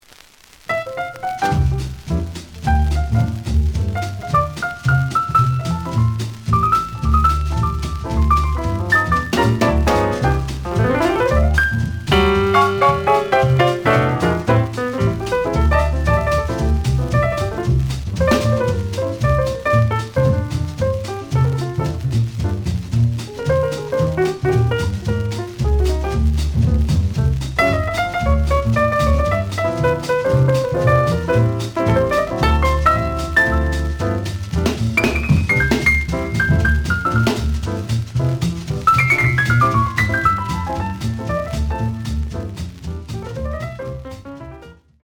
The audio sample is recorded from the actual item.
●Genre: Jazz Funk / Soul Jazz
Some noise on both sides.